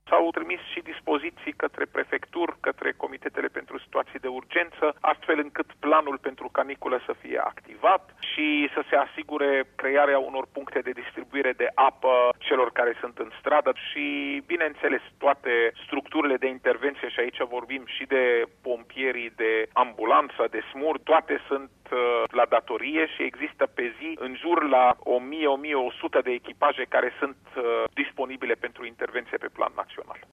Raed Arafat a precizat pentru RRA ce măsuri au fost luate pentru a veni în ajutorul populaţiei: